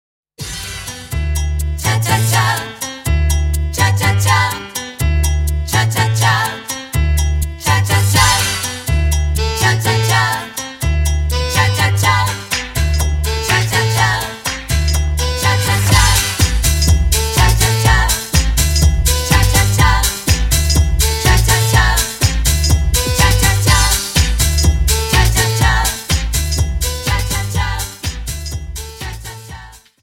Dance: Cha Cha